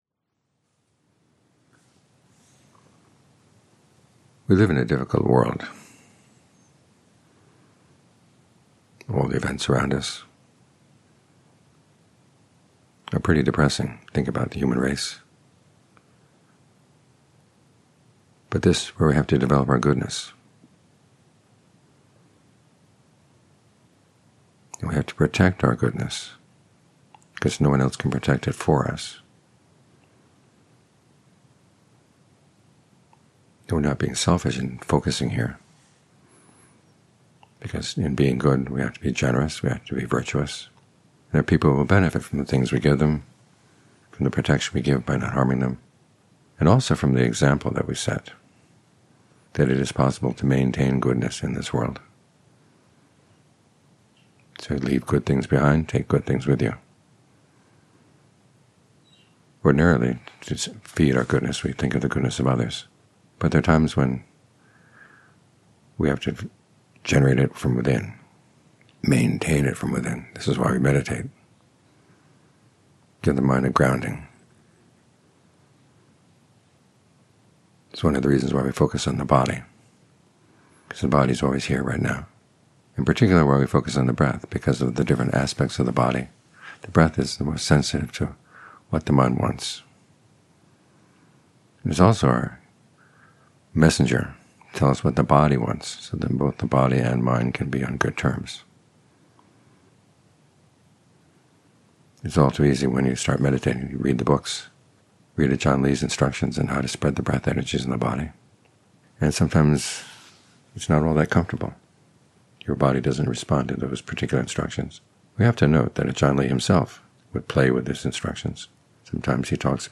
Evening Talks